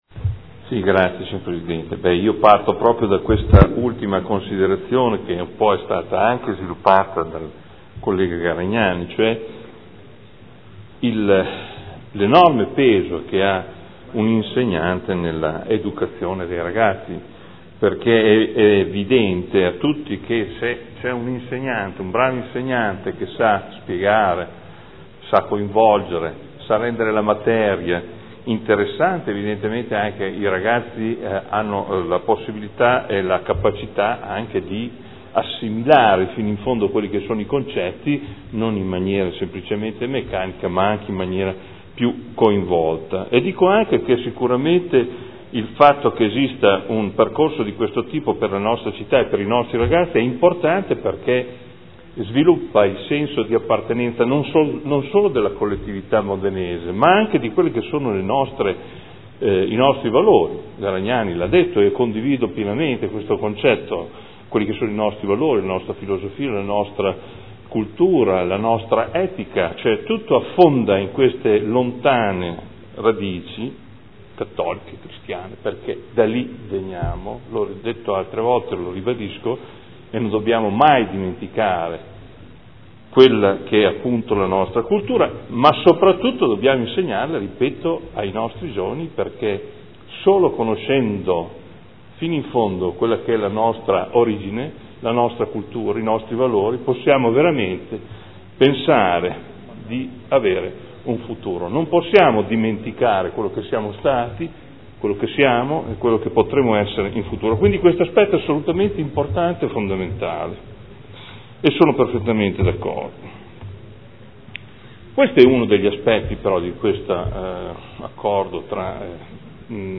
Seduta del 22/07/2013 Delibera. Museo Civico d’Arte - Protocollo d’intesa con i Musei del Duomo per la realizzazione di percorsi didattici e laboratori sul sito Unesco all’interno dei Musei del Duomo- Approvazione